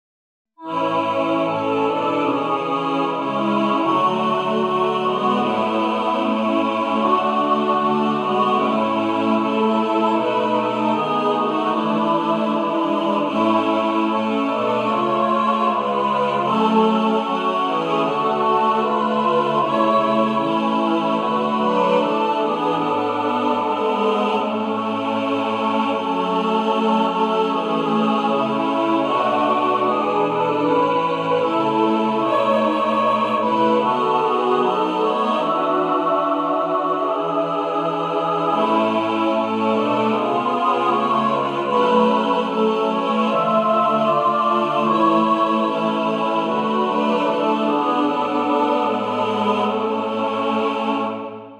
A beautiful, sacred hymn